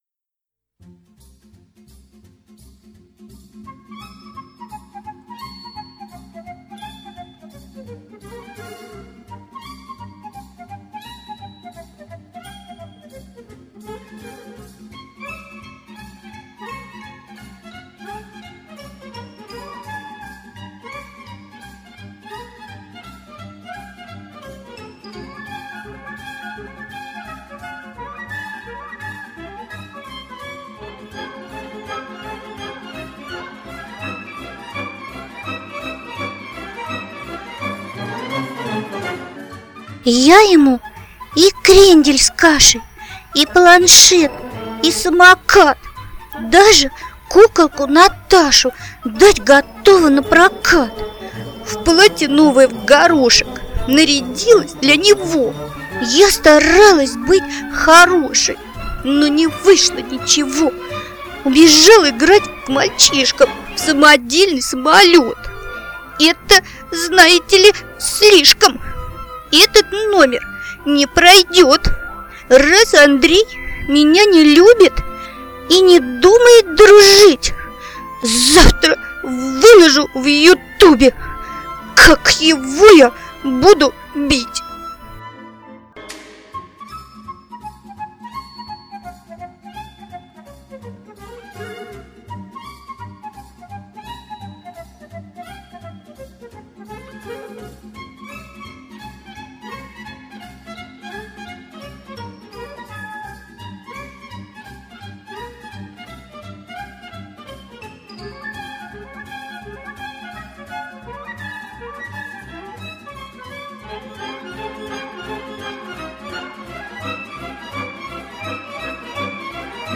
декламация